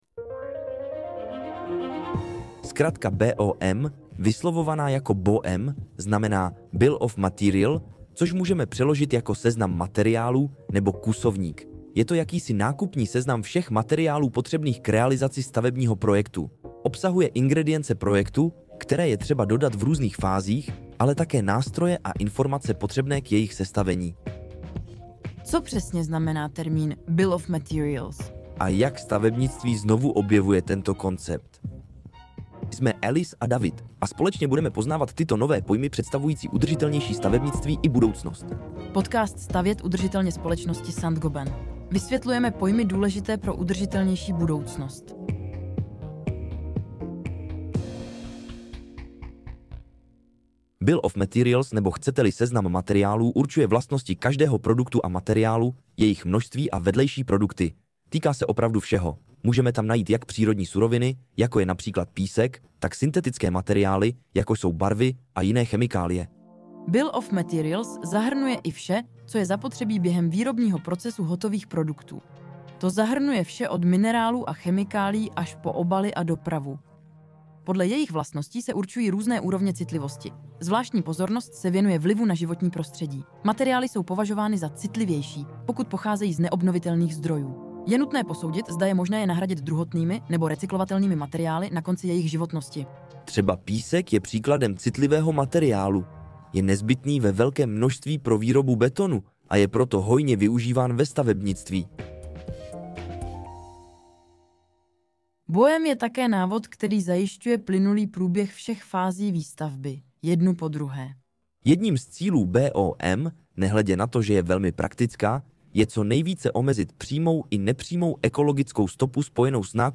🌱 Tento podcast pro vás z francouzského originálu přeložila a také namluvila umělá inteligence, aby i ten byl ekologicky šetrný a pomáhal nám snižovat uhlíkovou stopu.